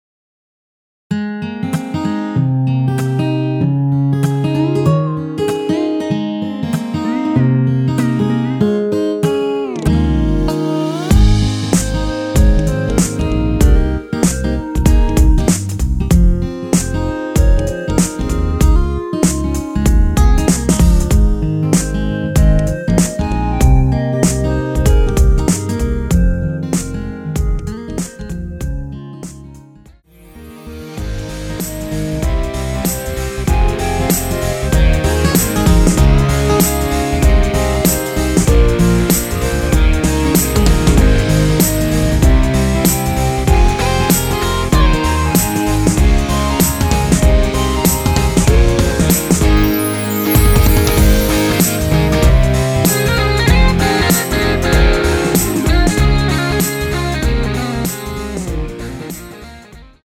원키 멜로디 포함된 MR입니다.
Ab
앞부분30초, 뒷부분30초씩 편집해서 올려 드리고 있습니다.
중간에 음이 끈어지고 다시 나오는 이유는